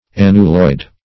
annuloid - definition of annuloid - synonyms, pronunciation, spelling from Free Dictionary Search Result for " annuloid" : The Collaborative International Dictionary of English v.0.48: Annuloid \An"nu*loid\, a. (Zool.) Of or pertaining to the Annuloida.